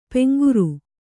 ♪ peŋguru